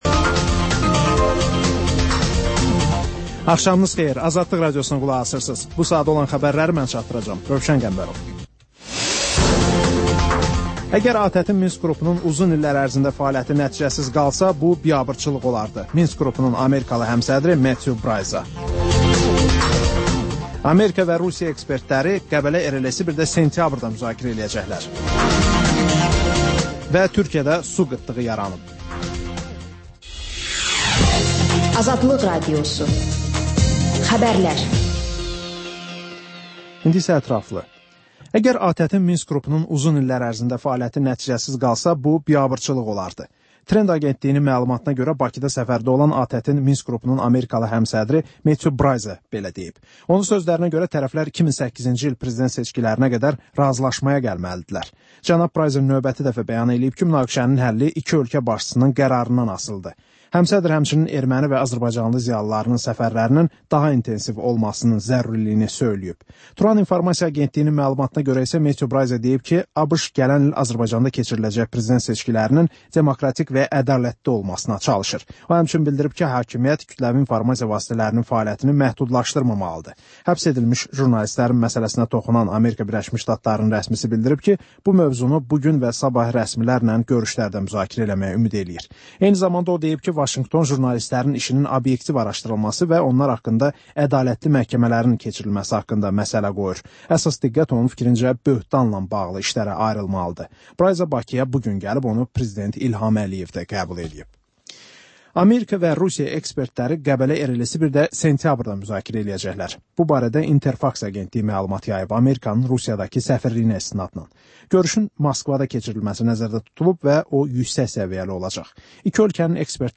Xəbərlər, müsahibələr, hadisələrin müzakirəsi, təhlillər, sonda TANINMIŞLAR verilişi: Ölkənin tanınmış simalarıyla söhbət